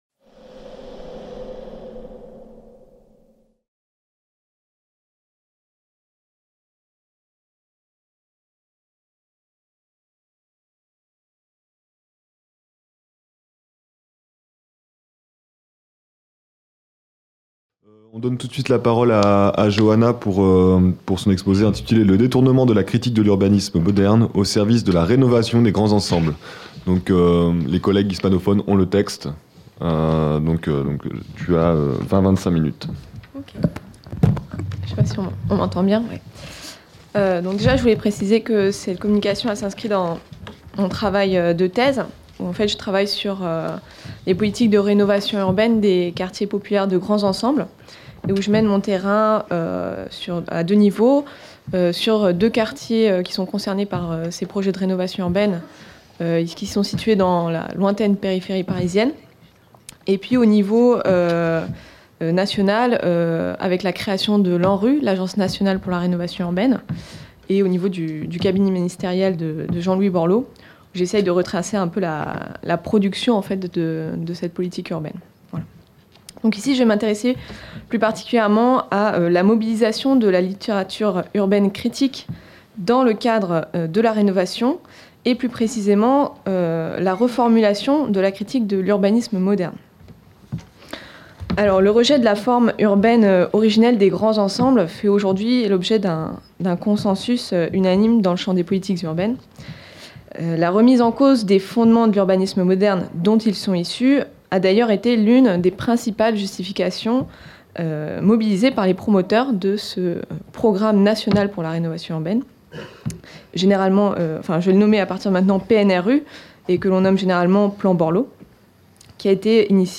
Cette communication a été filmée lors du colloque international "Le droit à Lefebvre" qui s'est déroulé du 29 au 31 mai 2018 à Caen.